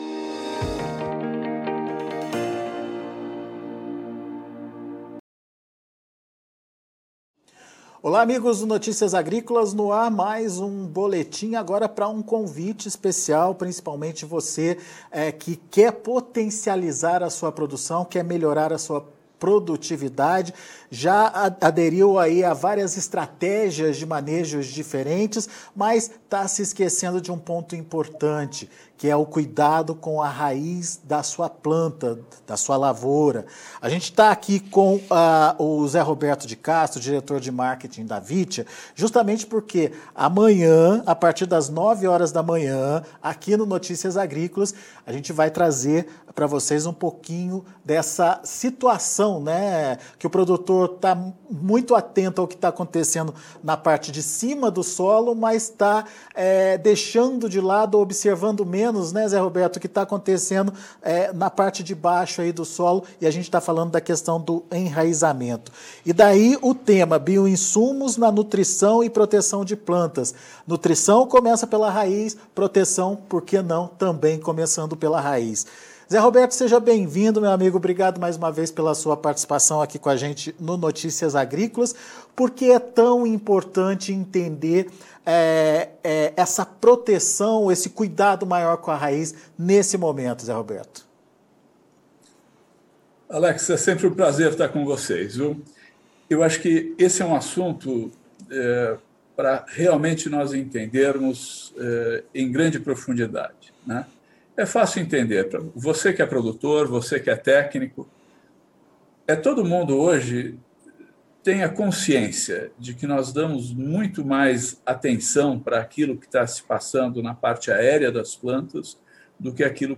Grupo Vittia promove live para discutir o uso de Bioinsumos na nutrição e proteção de plantas nesta terça-feira(13) a partir das 9h pelo site Notícias Agrícolas